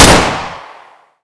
usp_unsil-1.wav